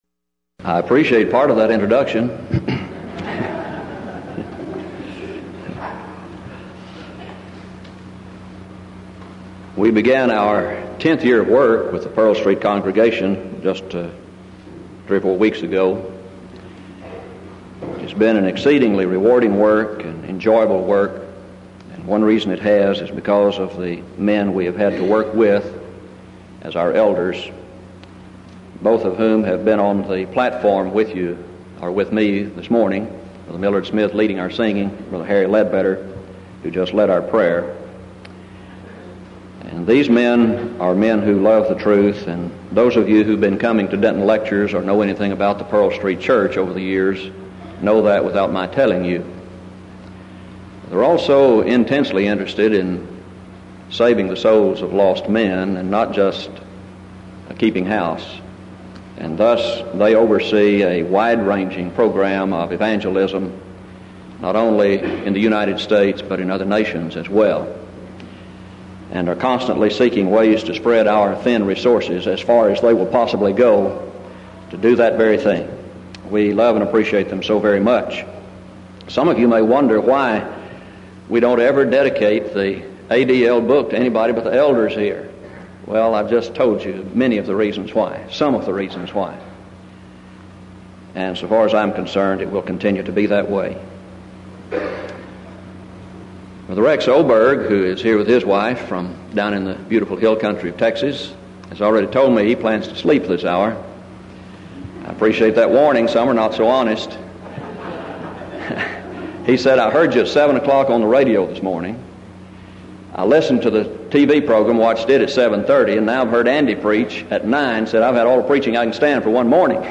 Event: 1989 Denton Lectures
lecture